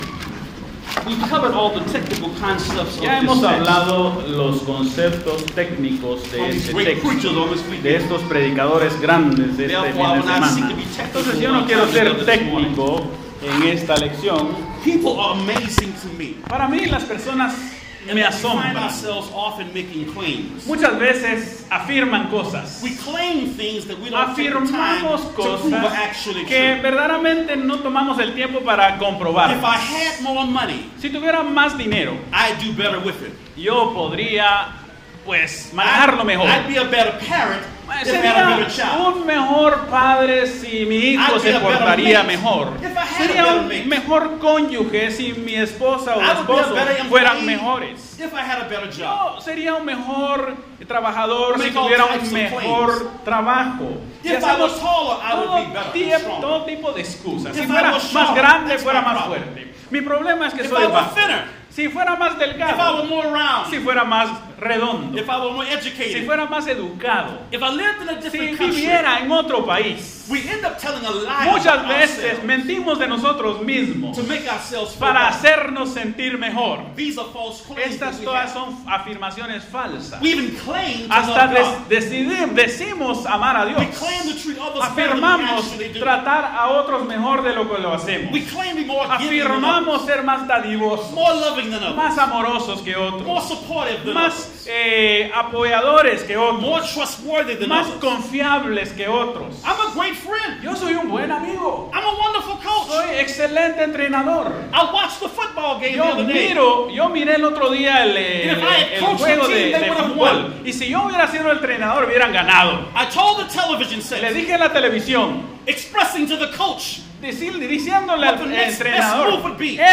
by admin | Apr 28, 2019 | ITL Lectureship 2019, Lectureships/Seminarios, Sermon